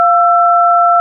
DTMF keypad frequencies (with sound clips)[12]
1209 Hz 1336 Hz 1477 Hz 1633 Hz